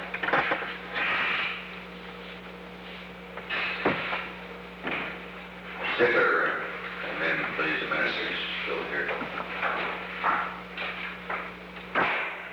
The Oval Office taping system captured this recording, which is known as Conversation 631-010 of the White House Tapes.